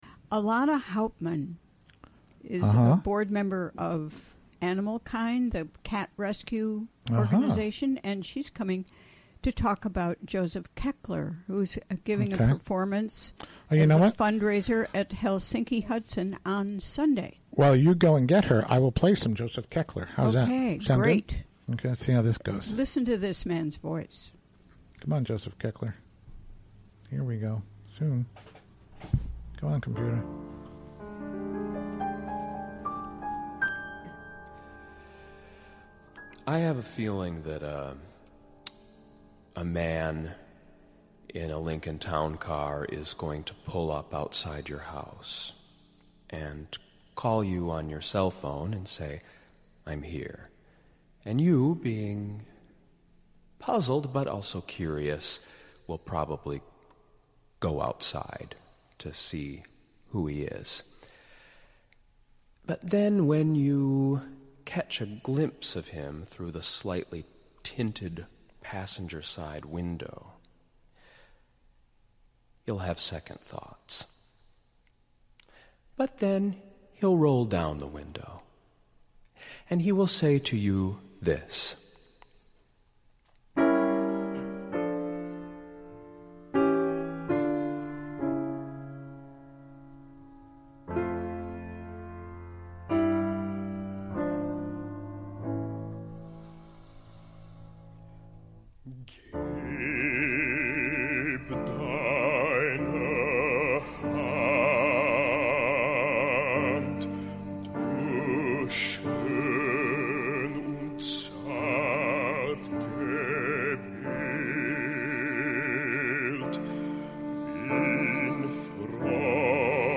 Recorded during the WGXC Afternoon Show Thursday, March 30, 2017.